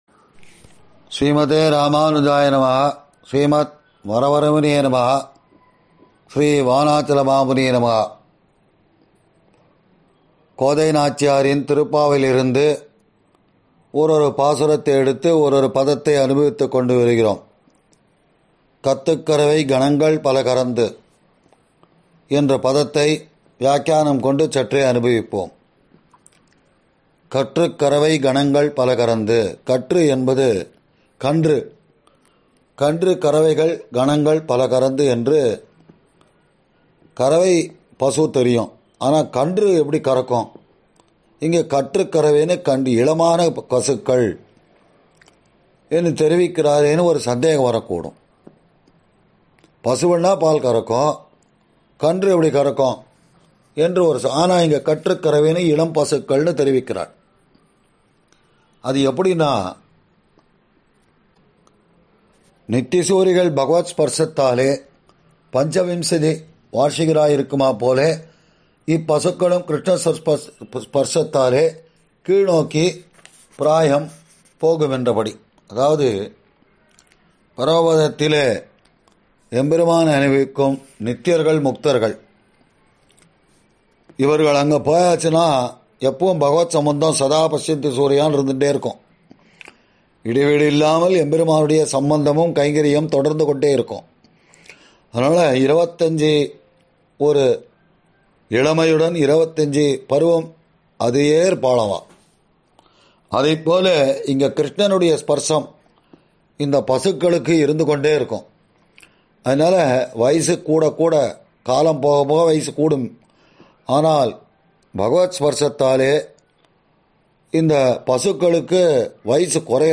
ஆறெனக்கு நின் பாதமே சரண் குழுமத்தினர் வழங்கும்சார்வரி ௵ மார்கழி ௴ மஹோத்ஸவ உபன்யாசம் –